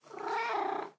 purreow2.ogg